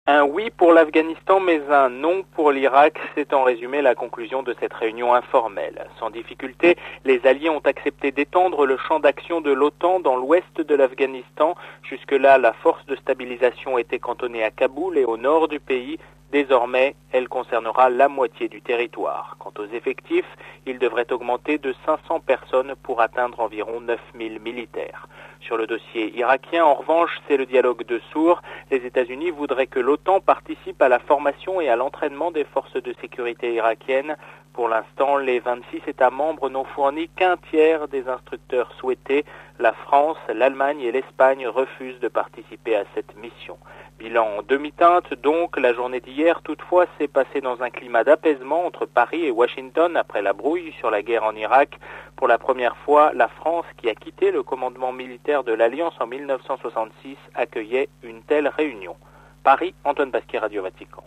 Les ministres de la Défense de l’Alliance transatlantique sont réunis depuis hier à Nice, dans le sud est de la France. Compte-rendu